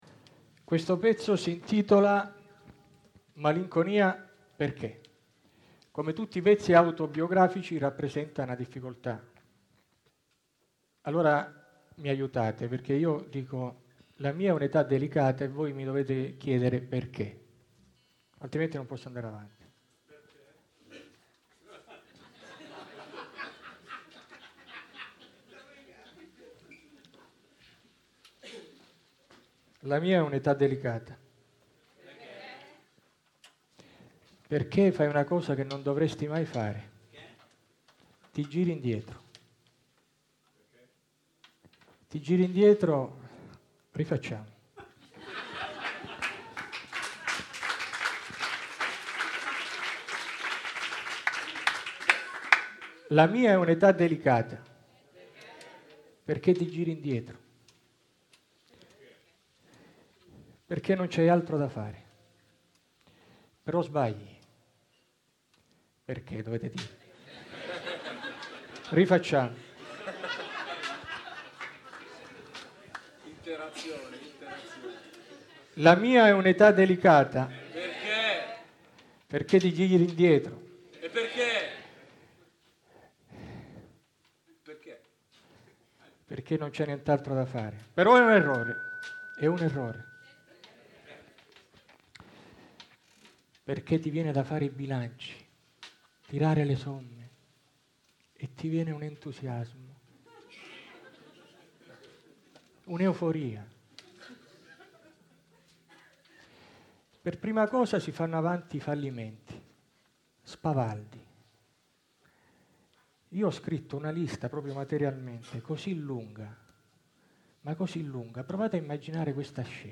monologo